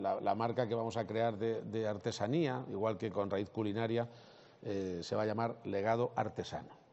>> Así lo ha anunciado el presidente de Castilla-La Mancha en la inauguración de la XLI edición de la Feria Regional de Artesanía